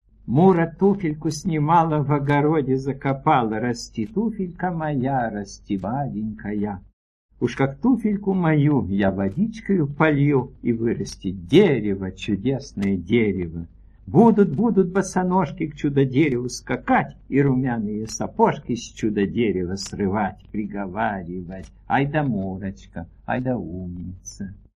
Index of /audio/Мелодия_детям/Сказки с пластинок 3/Чуковский читает автор